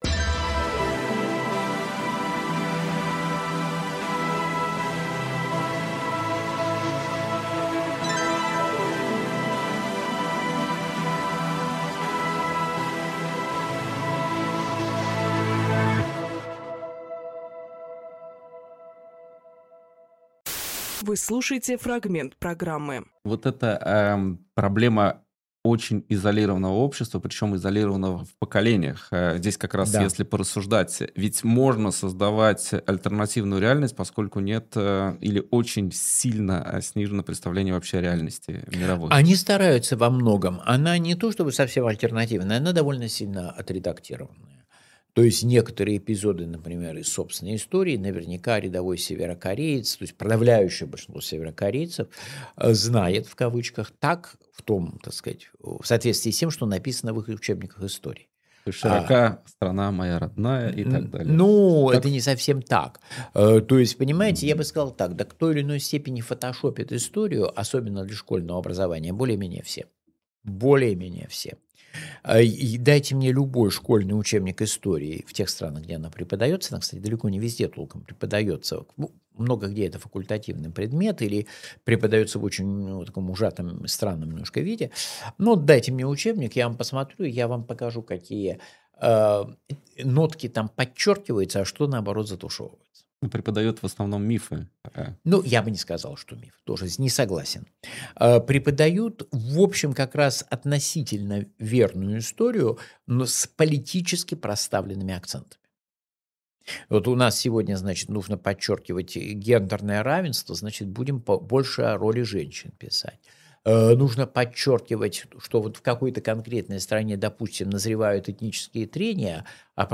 Фрагмент эфира от 24.02.26